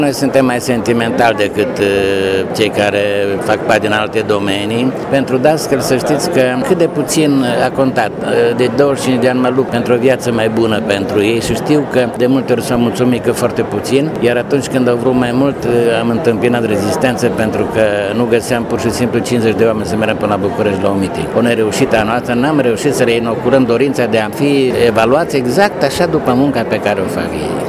Prima ediție a Galei Educației Mureșene a avut loc astăzi la Palatul Culturii și a fost dedicată dascălilor pensionari, care au primi diplome și medalii.